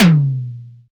Index of /90_sSampleCDs/Roland L-CDX-01/DRM_Analog Drums/TOM_Analog Toms
TOM DDR TOM2.wav